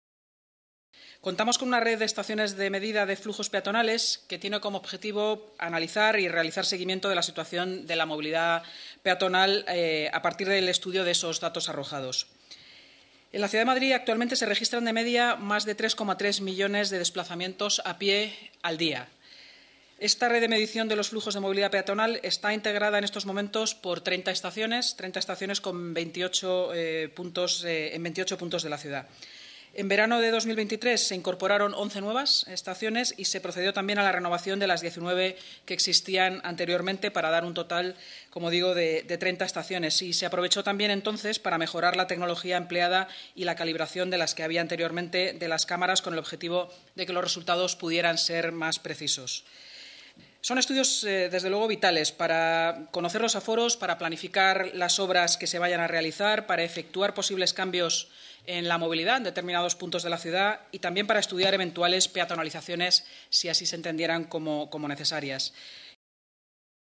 Nueva ventana:Declaraciones de la delegada de Seguridad y Emergencias y portavoz municipal, InmaSanz